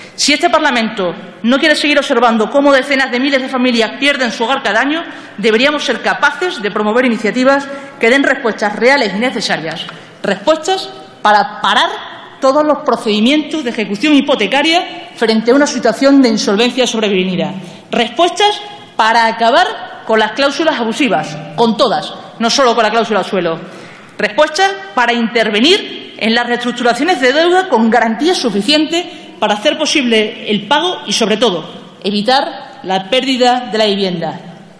Fragmento de la intervención de Leire Iglesias en el pleno defendiendo una iniciativa para eliminar las cláusulas suelo de los créditos hipotecarios 18/11/2014